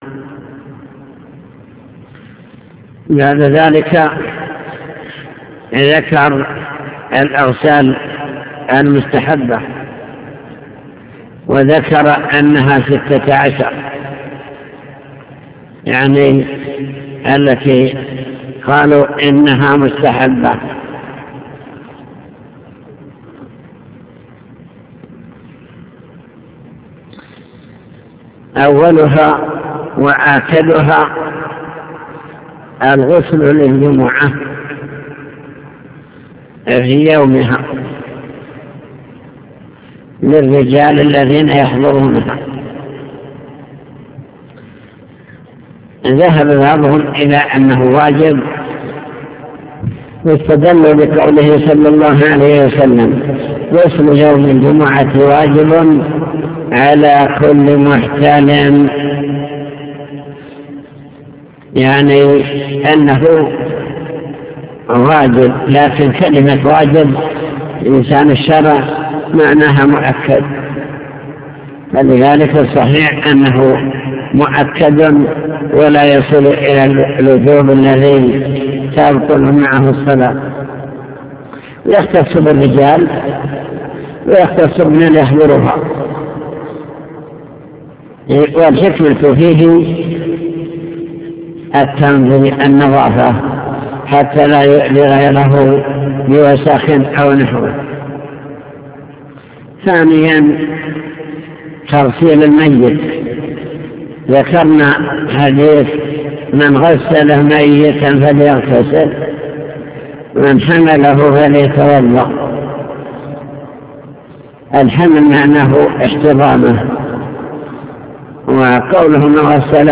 المكتبة الصوتية  تسجيلات - كتب  شرح كتاب دليل الطالب لنيل المطالب كتاب الطهارة باب الغسل